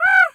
pgs/Assets/Audio/Animal_Impersonations/crow_raven_call_squawk_06.wav
crow_raven_call_squawk_06.wav